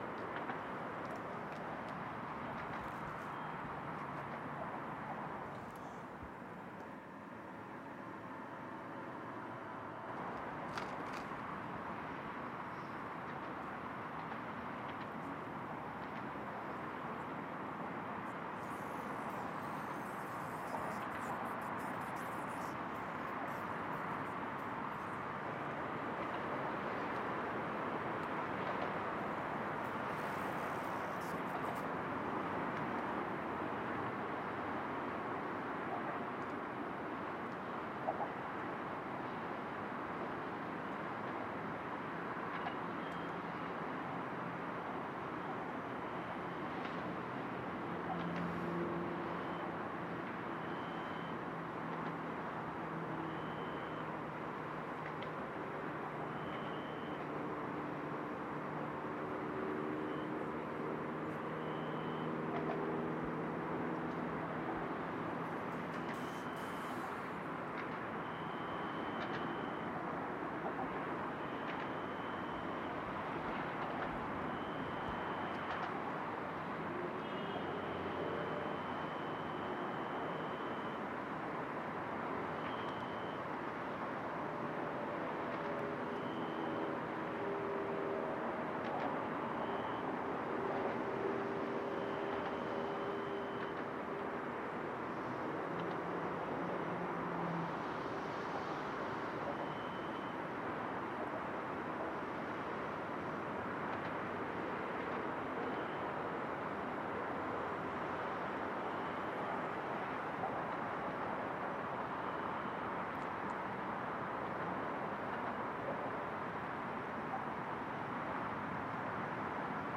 音乐猎人 城市的声音 " Azotea 遥远的交通
Tag: at825 交通 缩放 现场录音 F4 me66 城市